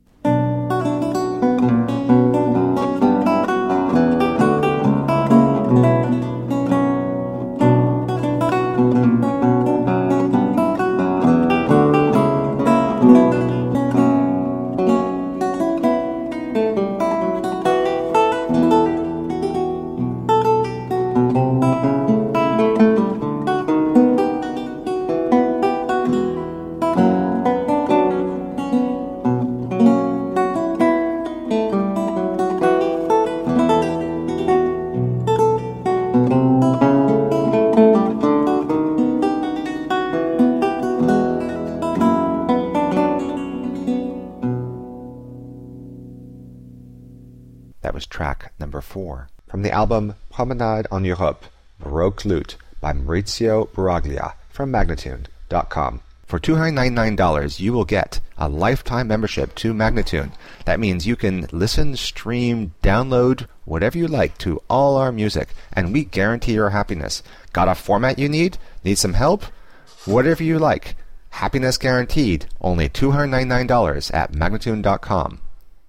A marvelous classical spiral of lute sounds.
Classical, Baroque, Instrumental
Lute